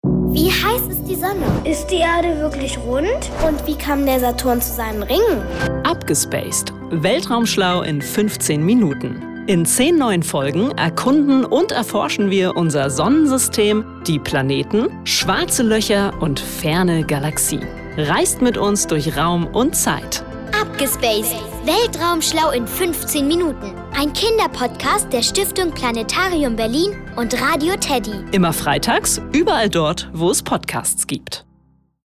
Podcast Trailer